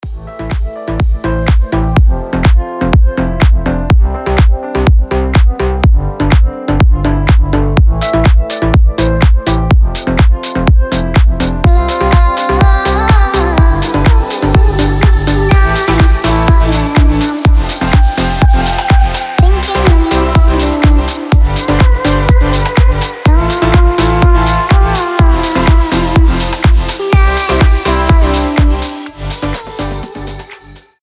• Качество: 320, Stereo
громкие
deep house
мелодичные
Club House
детский голос